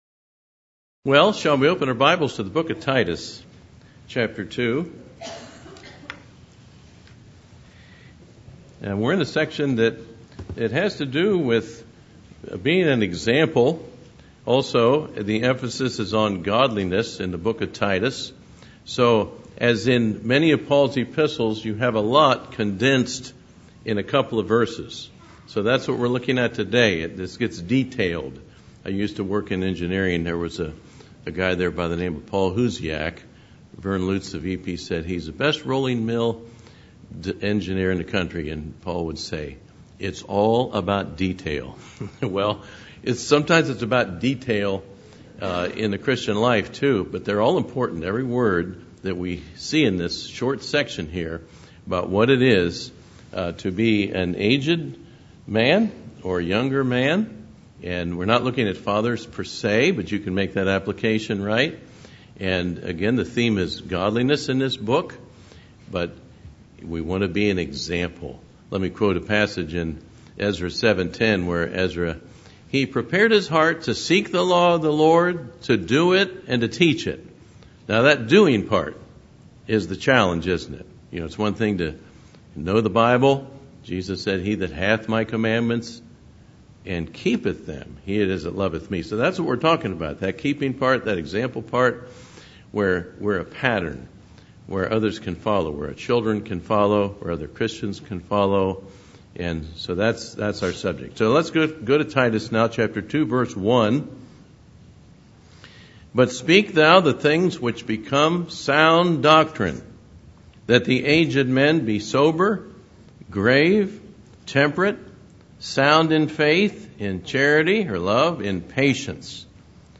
6-8 Service Type: Morning Worship Topics: Father's Day